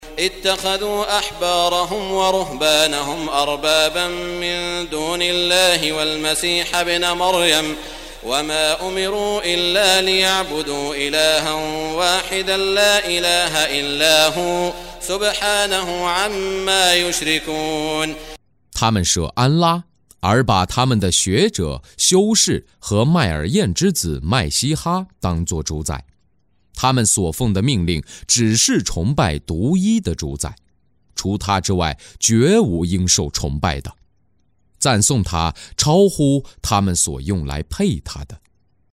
中文语音诵读的《古兰经》第（讨拜）章经文译解（按节分段），并附有诵经家沙特·舒拉伊姆的朗诵